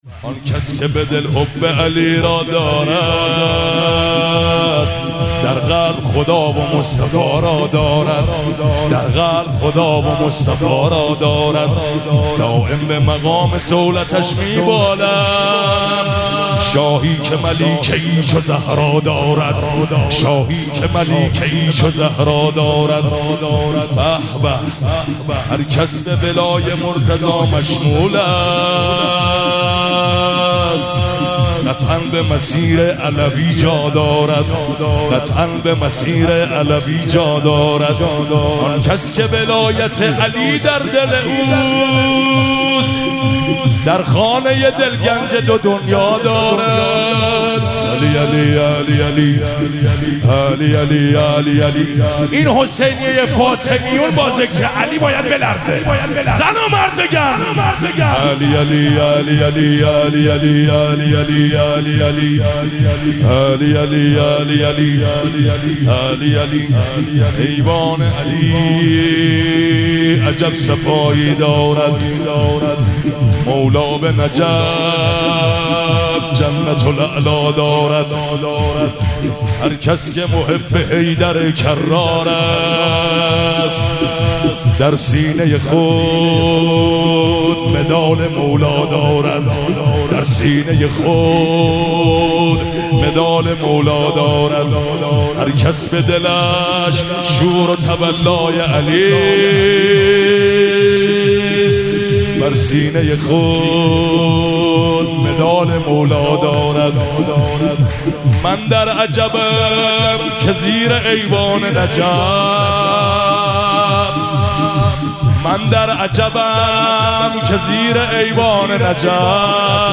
مراسم جشن شب اول ویژه برنامه عید سعید غدیر خم 1444